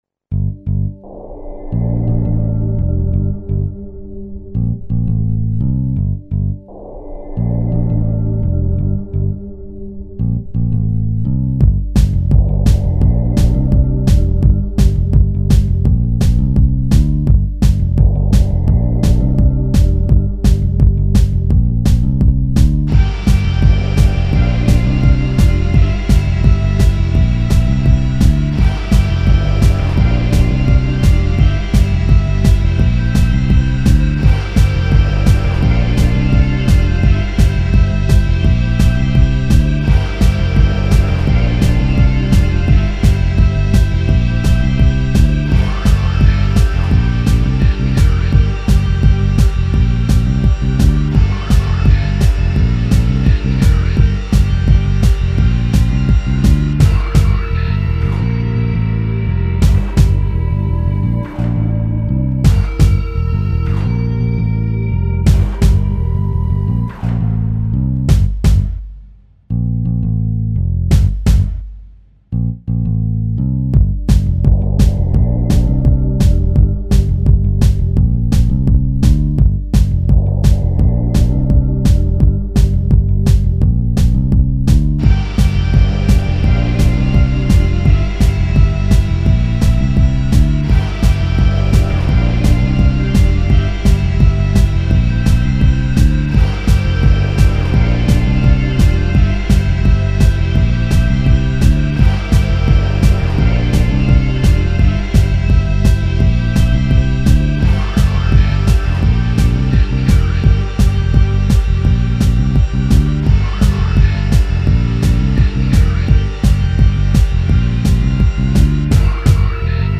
A series of acts...mechanical in nature.